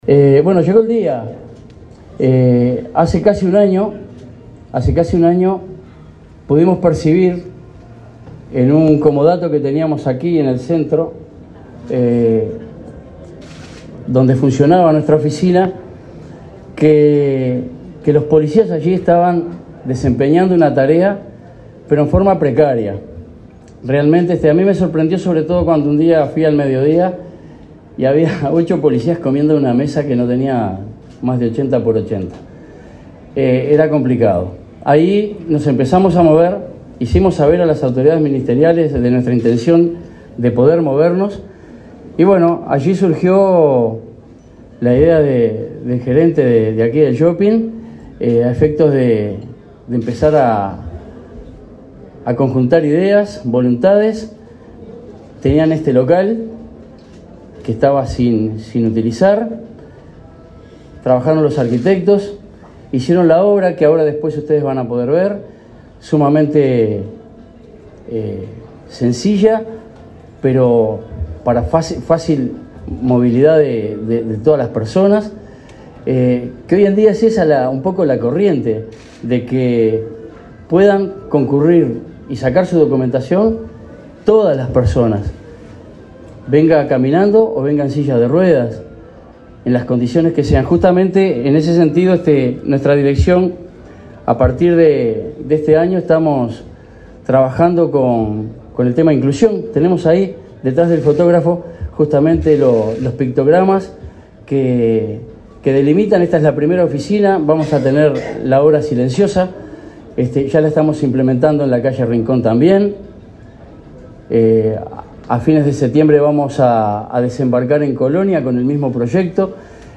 Palabra de autoridades del Ministerio del Interior
Declaraciones del ministro del Interior, Luis Alberto Heber